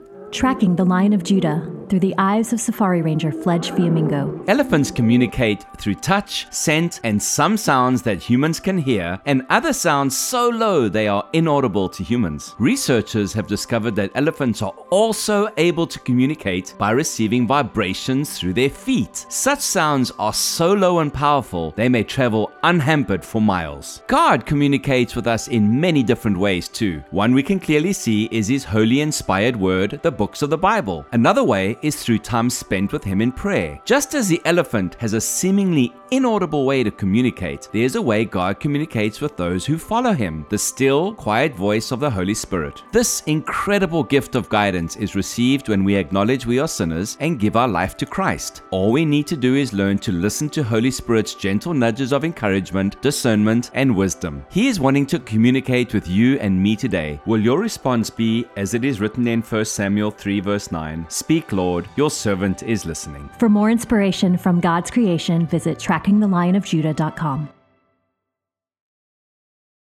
Elephant-infrasound.m4a